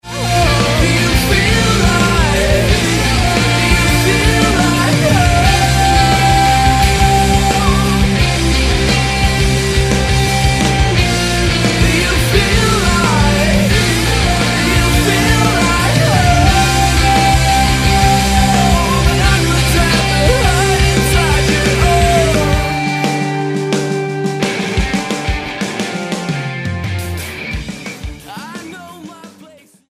STYLE: Rock
dense, guitar-layered rock
Falling somewhere between power pop and metal